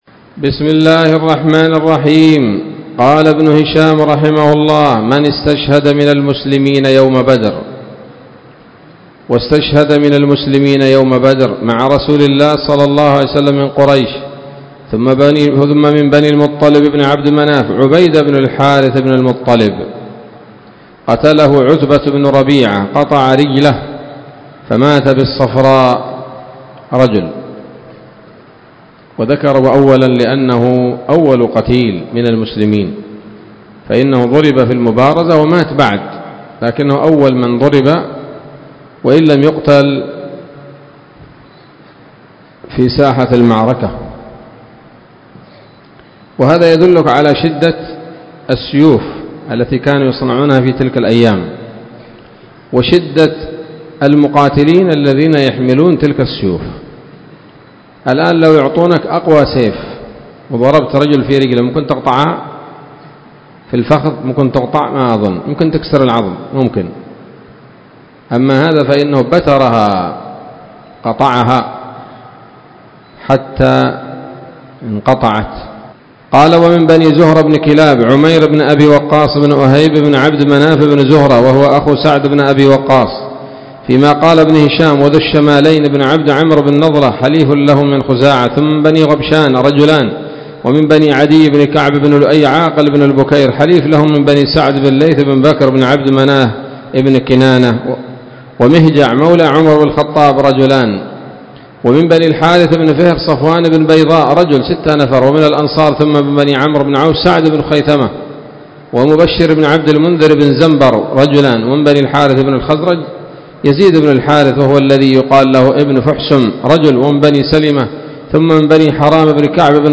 الدرس التاسع والثلاثون بعد المائة من التعليق على كتاب السيرة النبوية لابن هشام